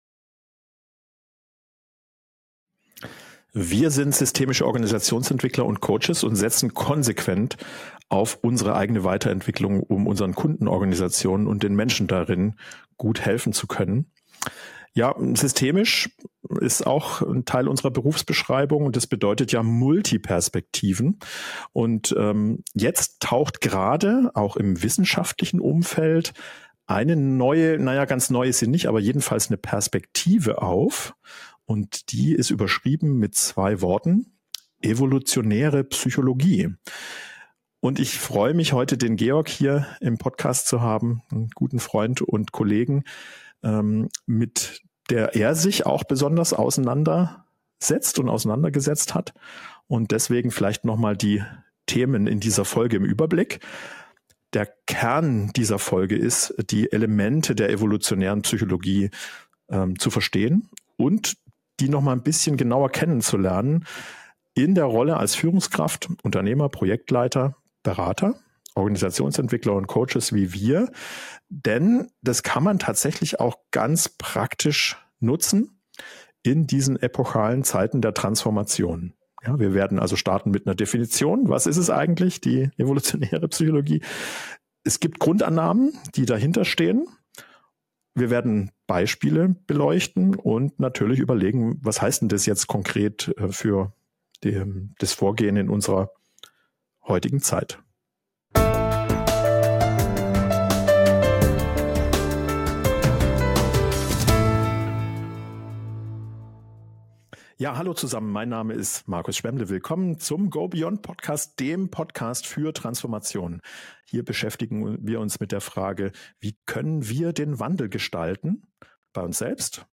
Im Gespräch geht es nicht nur um die Theorie, sondern auch um persönliche Erfahrungen, Aha-Momente und unerwartete Erkenntnisse.